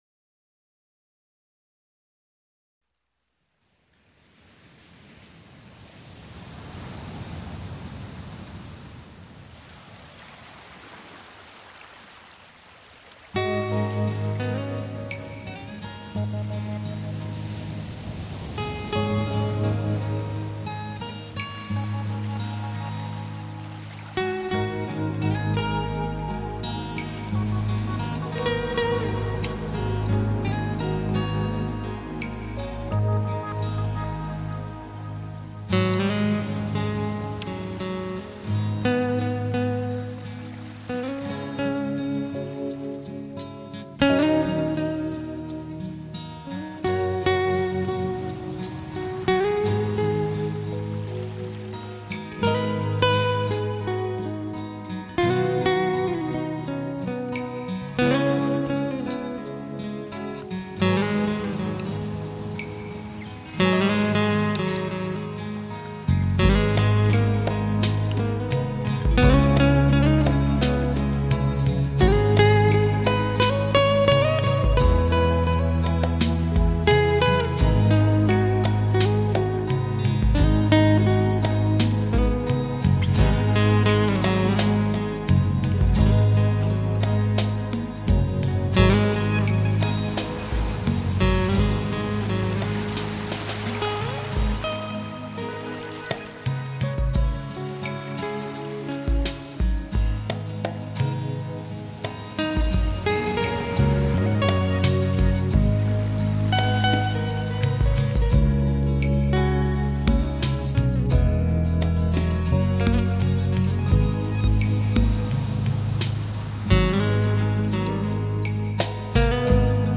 浪漫新世纪音乐
萨克斯风
吉他
贝斯
录音师：高雄亚洲数位录音室
浪漫华丽的音乐旅程